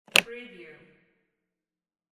Briefcase Lock Wav Sound Effect #7
Description: The sound of a briefcase lock snapping open or close
Properties: 48.000 kHz 16-bit Stereo
Keywords: briefcase, open, opening, close, closing, latch, unlatch, business, case, lock, locking, unlock, unlocking, snap, click
briefcase-lock-preview-7.mp3